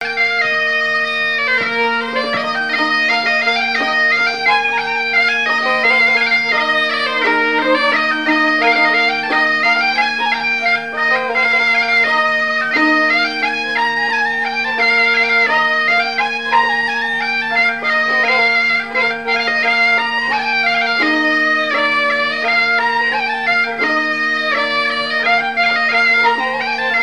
Joli bonnet rose, par Sonneurs de veuze
gestuel : à marcher
Airs joués à la veuze et au violon et deux grands'danses à Payré, en Bois-de-Céné
Pièce musicale inédite